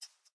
25w18a / assets / minecraft / sounds / mob / rabbit / idle3.ogg